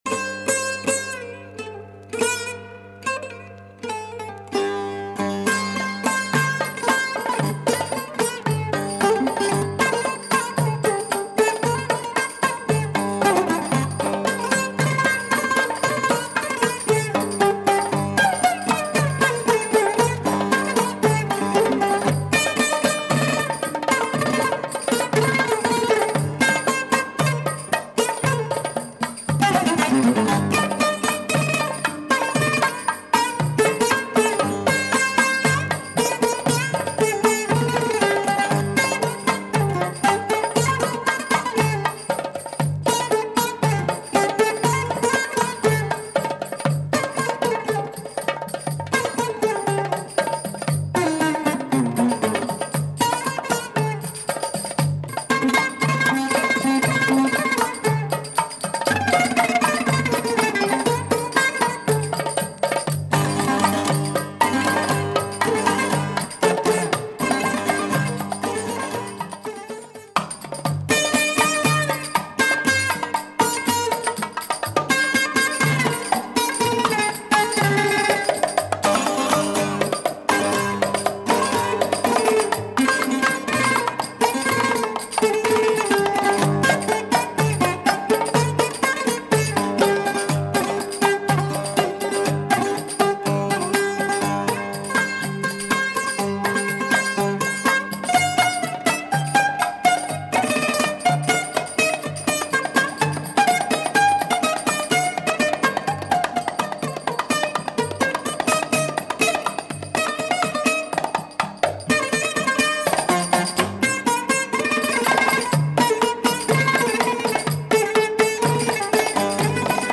Belly dance groove with buzuki